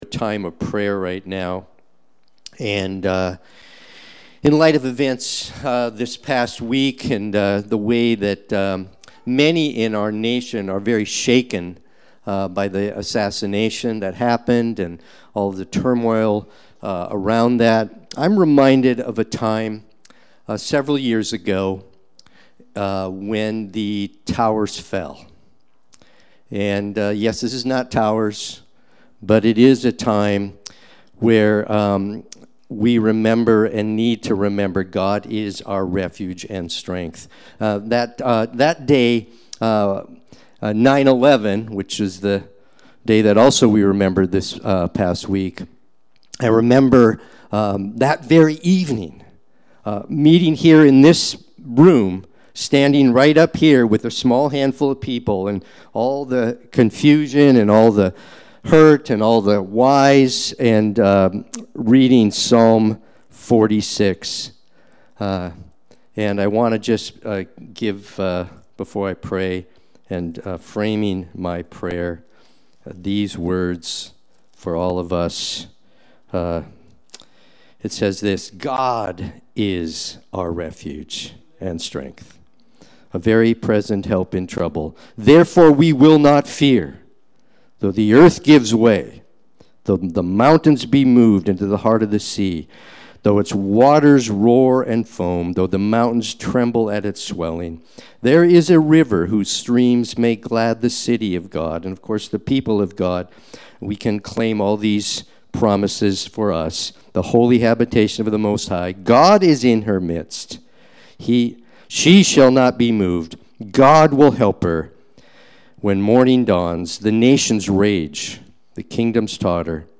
Mark 1:1-8 Watch the replay or listen to the sermon.
Sunday-Worship-main-91425.mp3